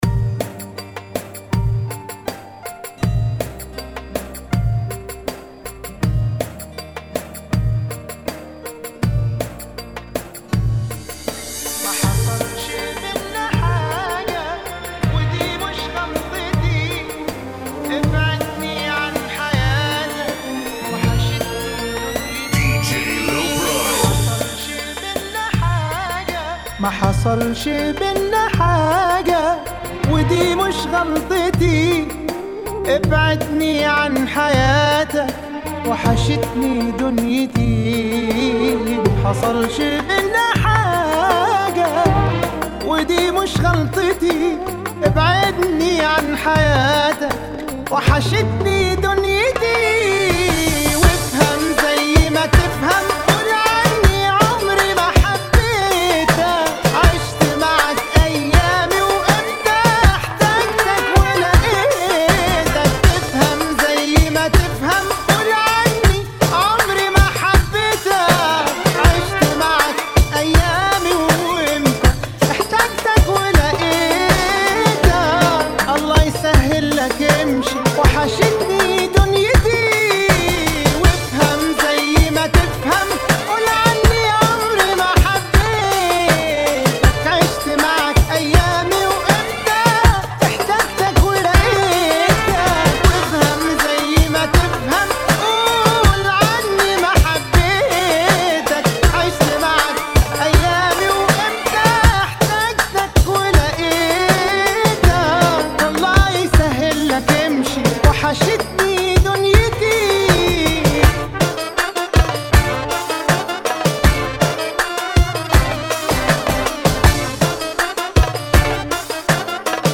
[ 80 bpm ]
بالهجه المصريه